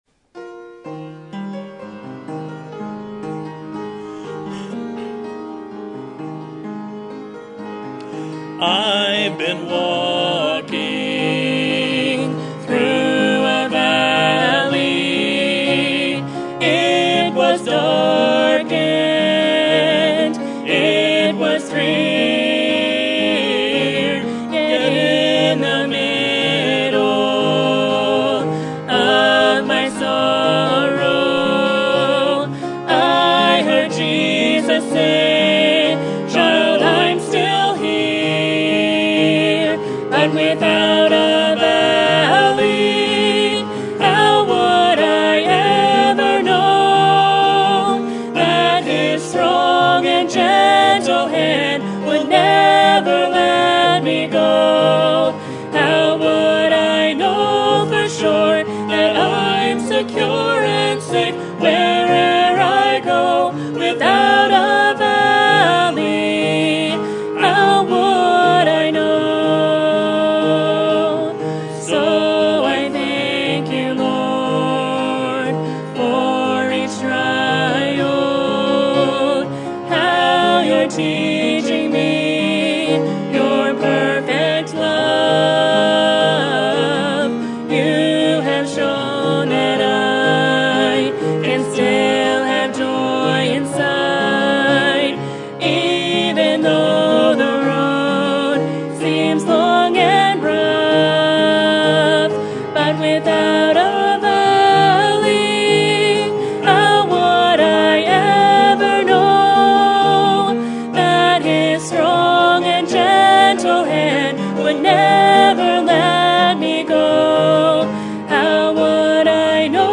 Sermon Type: Series Sermon Audio: Sermon download: Download (24.47 MB) Sermon Tags: Exodus Rapture Leadership Teaching